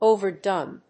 音節òver・dóne 発音記号・読み方
/ovəɻˈdɔn(米国英語), ˈəʊvɜ:ˈdʌn(英国英語)/